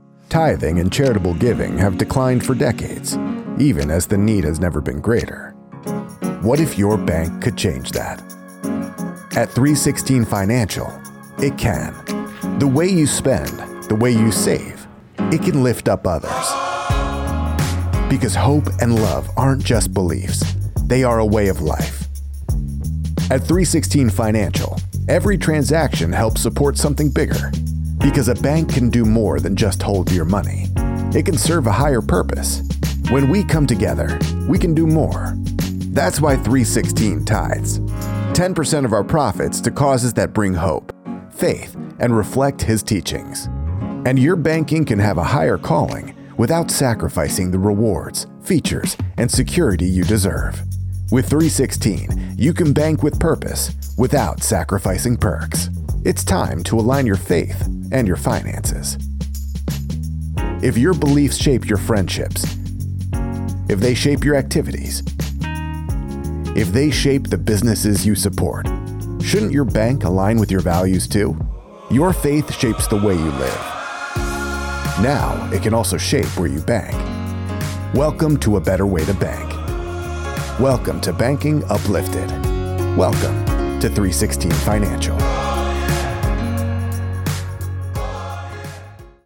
Male
He specializes in high energy, playful, friendly, tech-savvy, cool, and trustworthy millennial reads.
-Neumann TLM 103 Condensor Microphone
Television Spots
Middle Aged Gritty Country
Words that describe my voice are real, conversational, youthful.